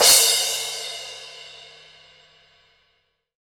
Index of /90_sSampleCDs/Sound & Vision - Gigapack I CD 1 (Roland)/CYM_CRASH mono/CYM_Crash mono
CYM CRA01.wav